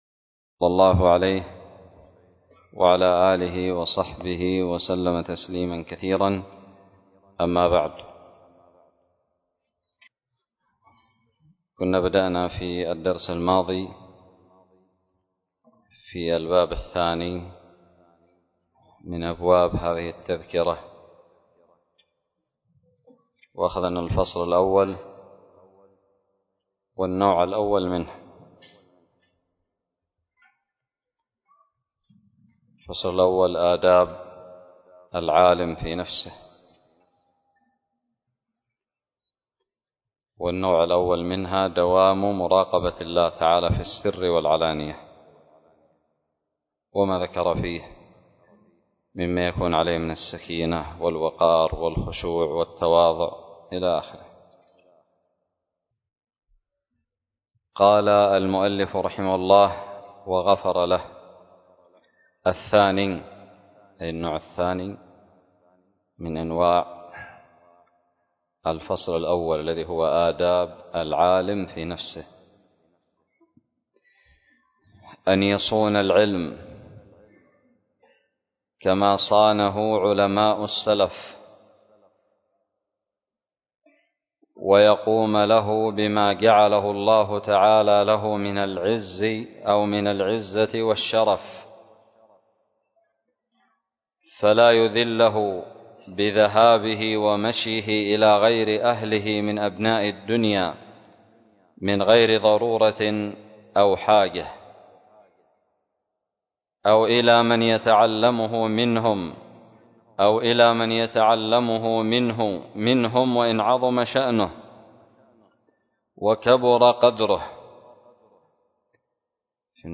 الدرس الخامس من شرح كتاب تذكرة السامع والمتكلم 1444هـ
ألقيت بدار الحديث السلفية للعلوم الشرعية بالضالع